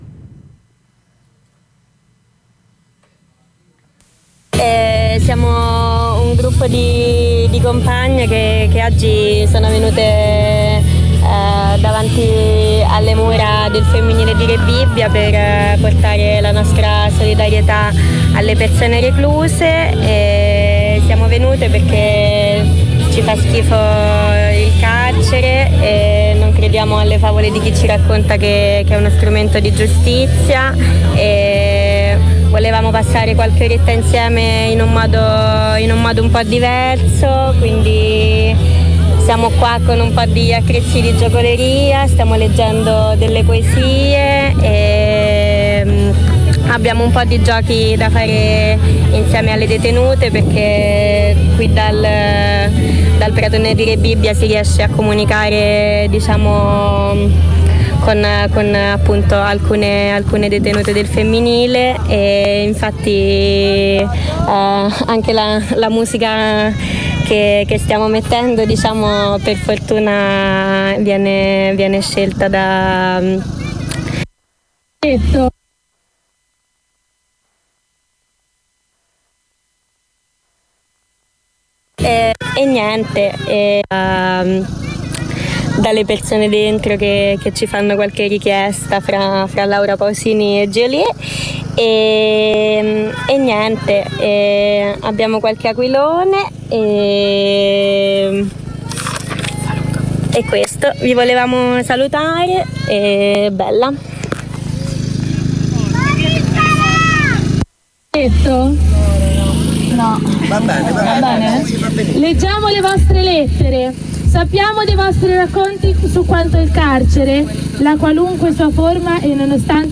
Le registrazioni della solidarietà portata Sabato 12 Aprile da un presidio transfemminista sotto la sez. femminile del carcere di Rebibbia.